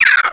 painf.wav